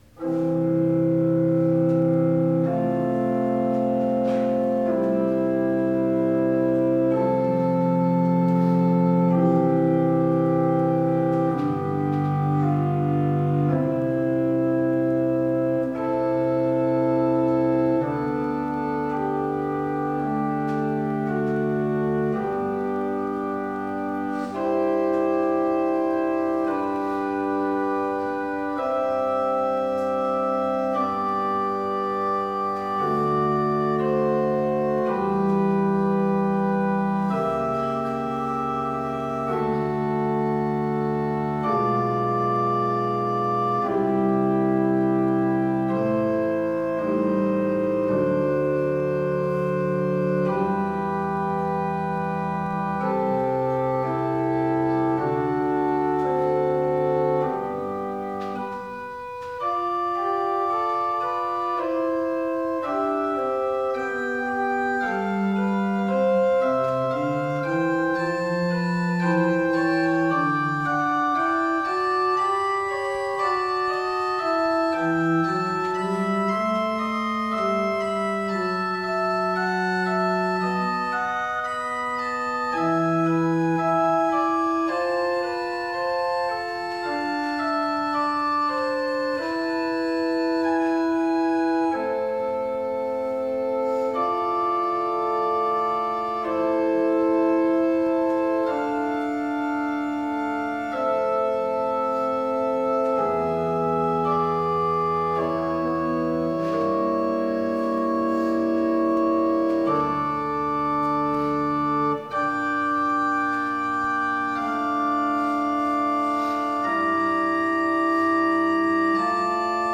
Gottesdienst am 16. November (Herzhausen)